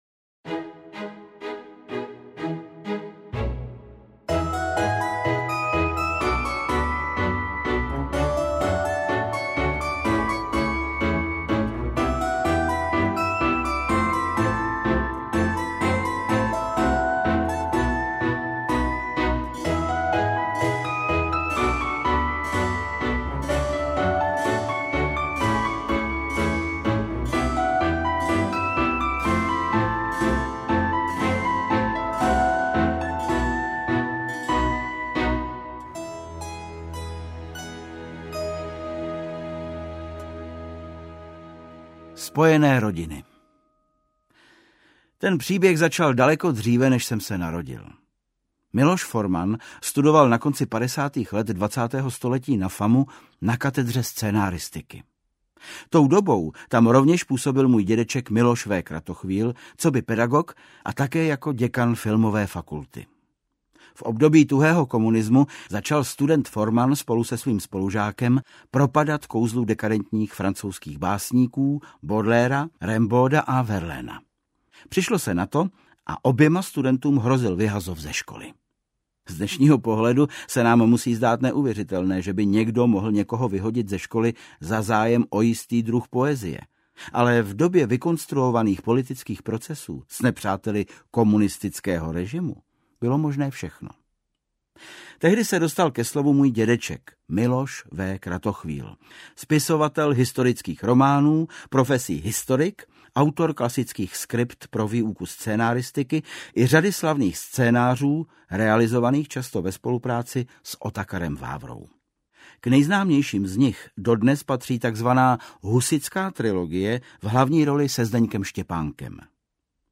Krásné čtení a ještě lepší poslech.